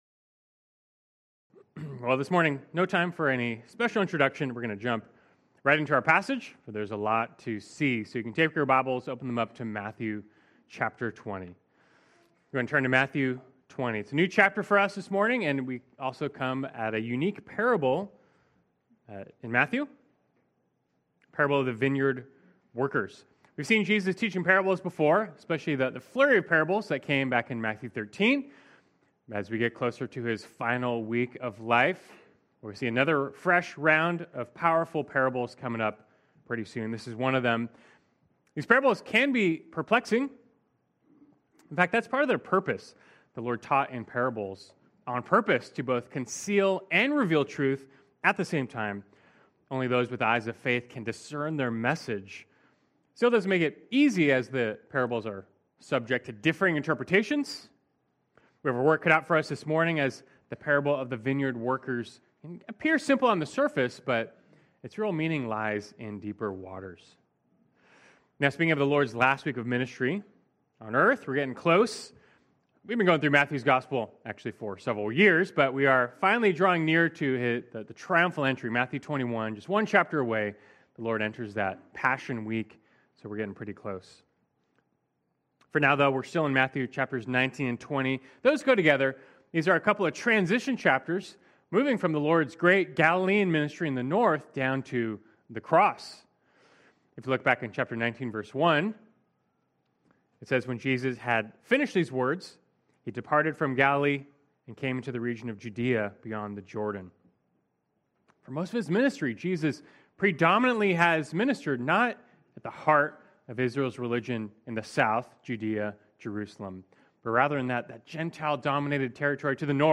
Berean Bible Church Sermons Podcast - Grace from First to Last | Free Listening on Podbean App